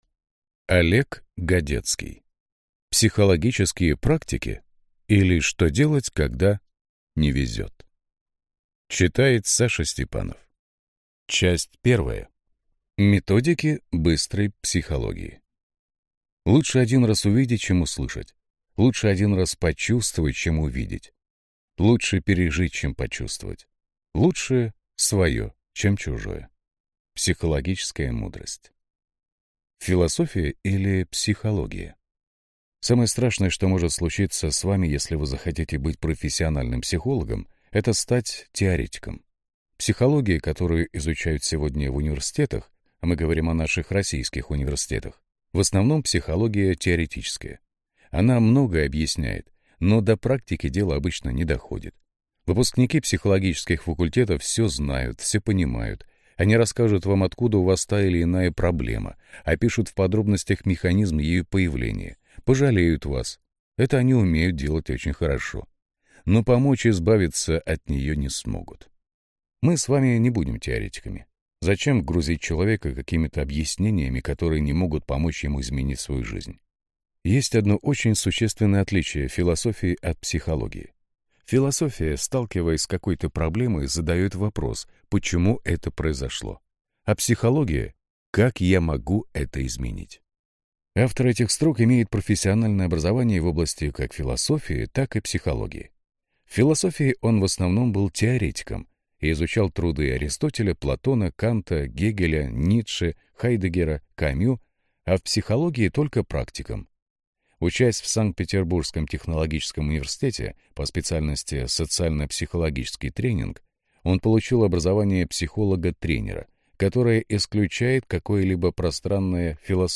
Аудиокнига Психологические практики, или Что делать, когда не везет | Библиотека аудиокниг